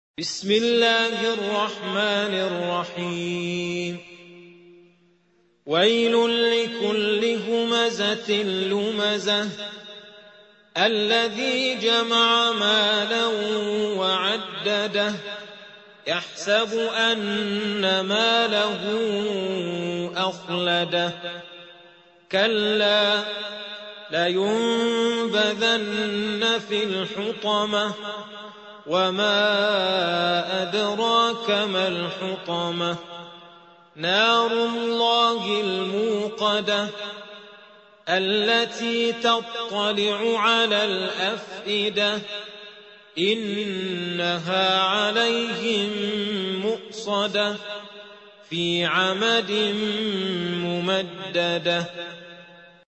سورة الهمزة | القارئ عبد الهادي كناكري